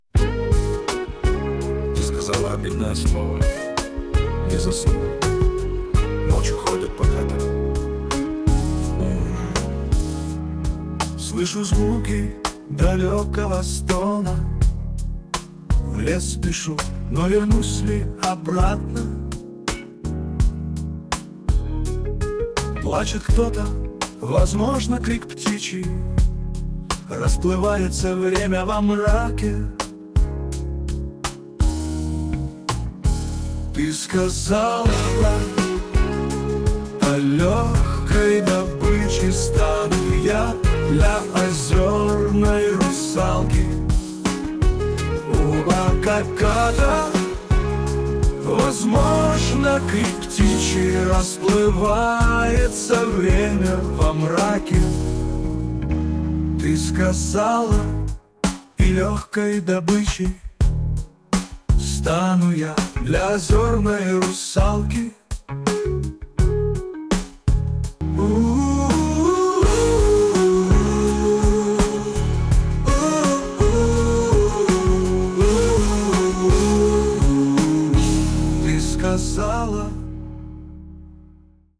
Блюз (1232)